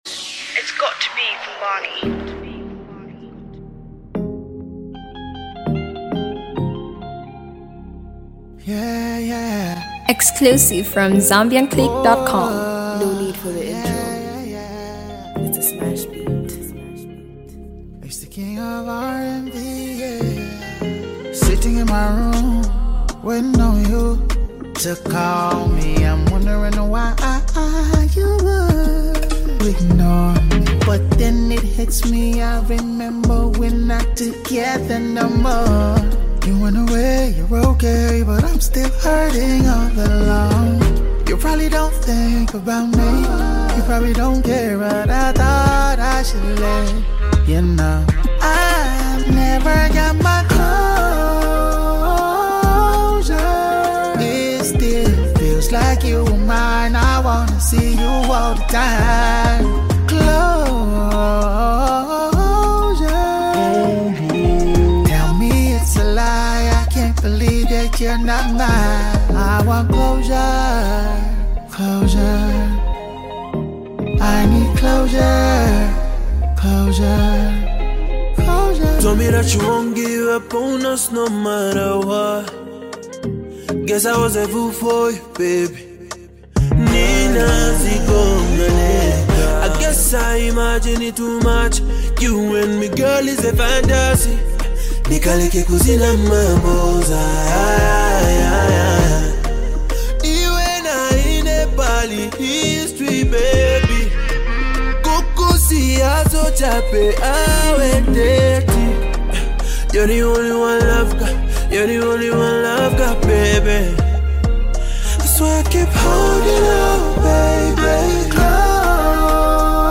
Zambian vocal powerhouse
RnB